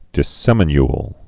(dĭ-sĕmə-nyl)